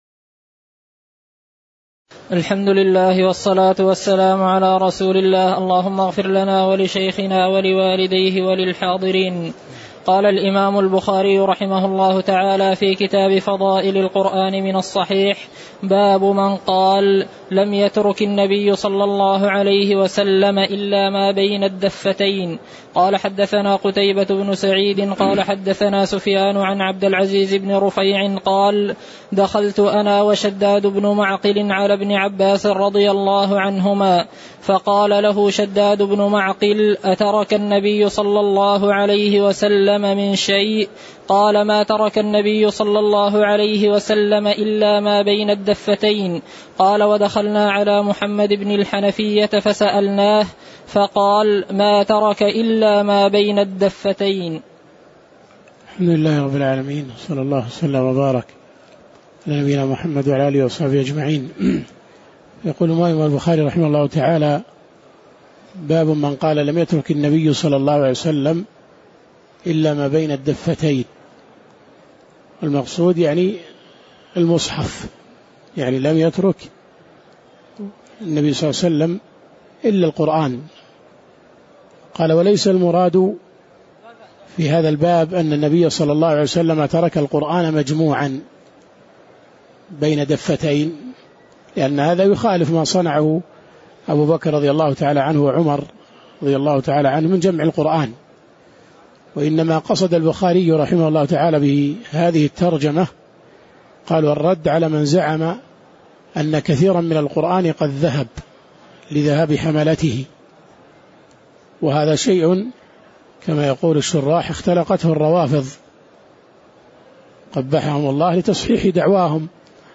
تاريخ النشر ١٨ رمضان ١٤٣٩ هـ المكان: المسجد النبوي الشيخ